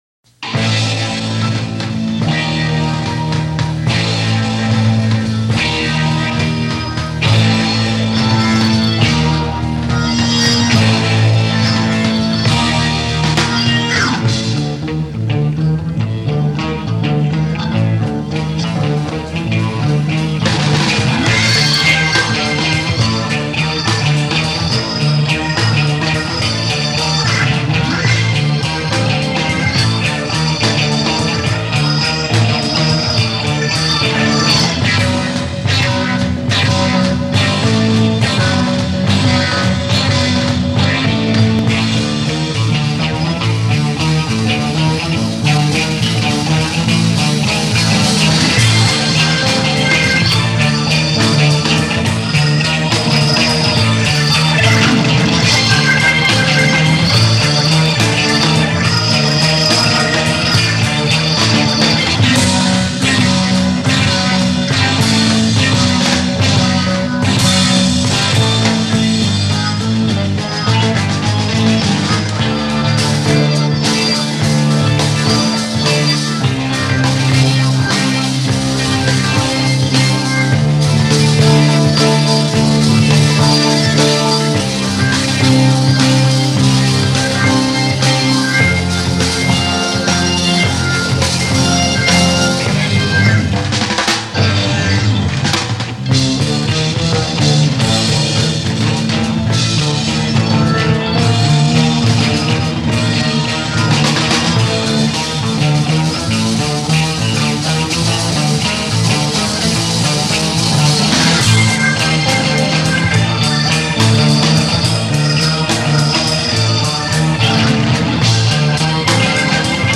drums and keyboards
guitar